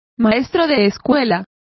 Complete with pronunciation of the translation of schoolmaster.